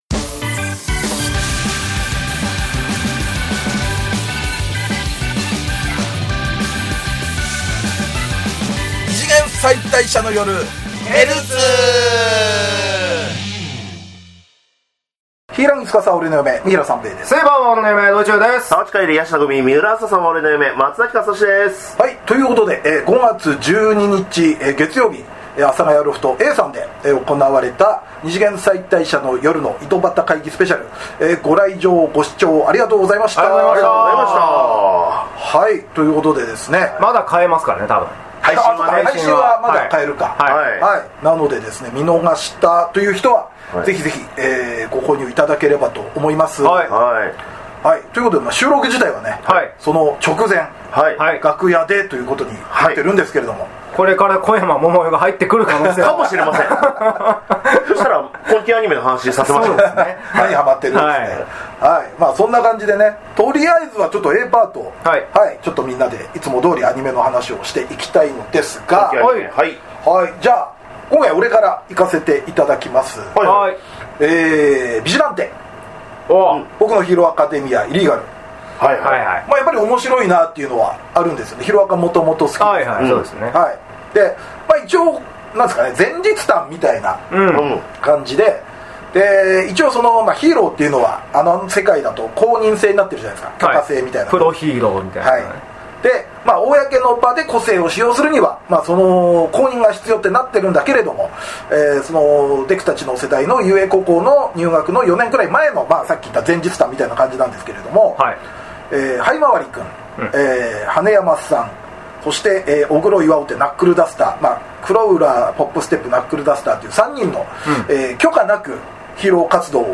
旬なテレビアニメの感想話やオススメ漫画話で楽しく陽気にバカ話！二次元キャラクターを嫁に迎えた芸人３人による、キャラ萌え中心アニメトークポッドキャストラジオです！